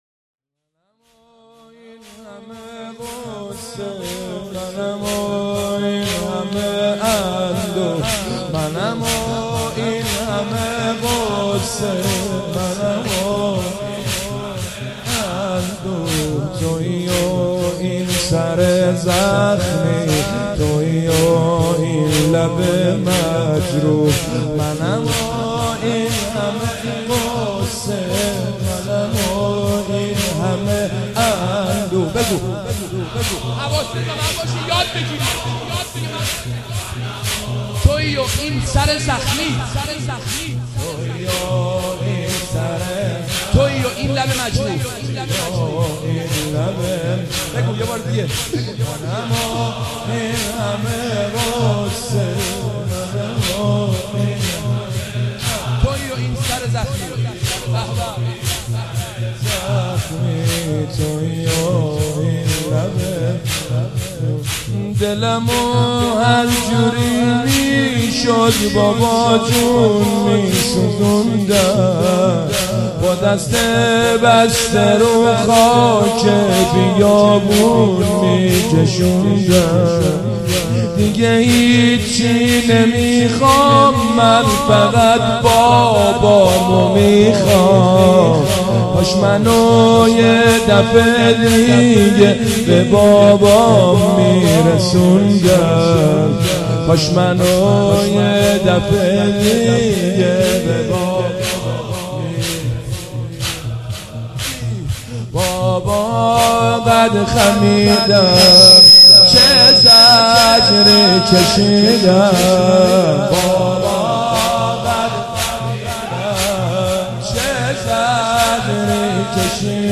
مداحی جدید
شب سوم دهه اول صفر 1397